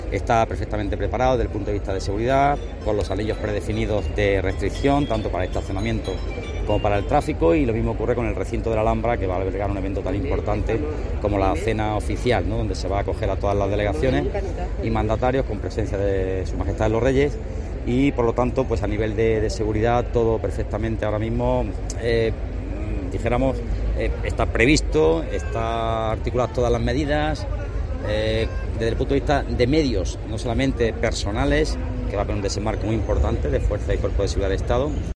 Pedro Fernández, delegado del gobierno en Andalucía
A preguntas de los periodistas en los actos de celebración con motivo del Día de la Policía Nacional en Sevilla, Fernández ha aludido a que es una logística que no se prepara "de un día para otro" sino que conlleva una tarea de "meses".